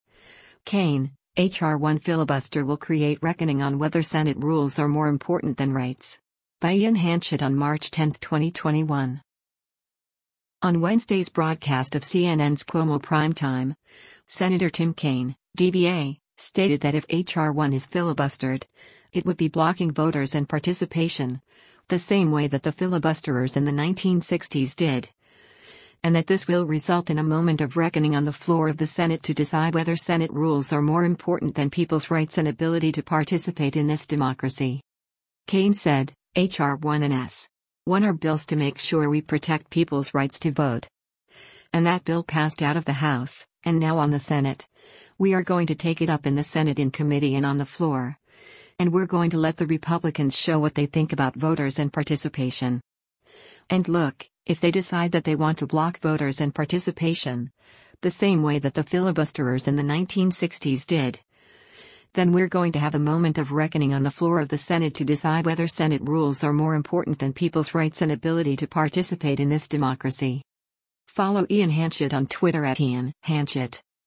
On Wednesday’s broadcast of CNN’s “Cuomo Primetime,” Sen. Tim Kaine (D-VA) stated that if H.R. 1 is filibustered, it would be blocking “voters and participation, the same way that the filibusterers in the 1960s did,” and that this will result in “a moment of reckoning on the floor of the Senate to decide whether Senate rules are more important than people’s rights and ability to participate in this democracy.”